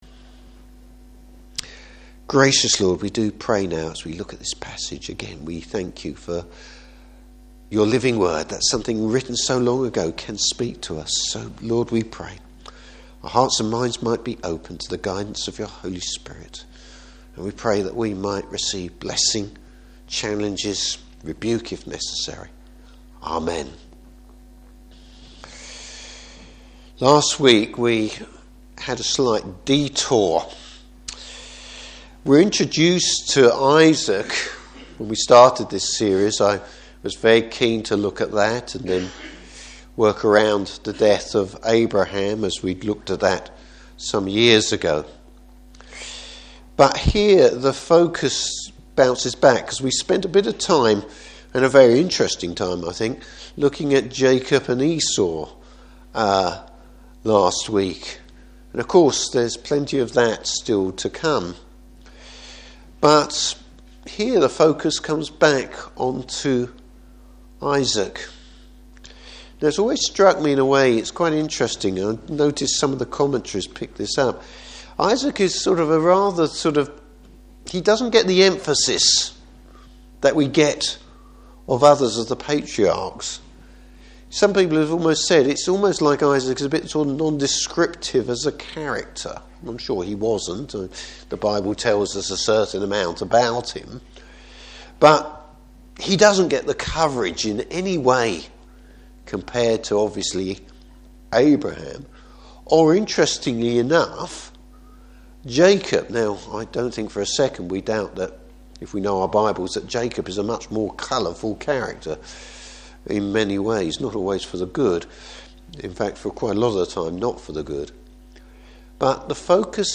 Service Type: Evening Service Jacob is blessed even when he fails!